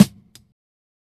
SNARE_TWISTED.wav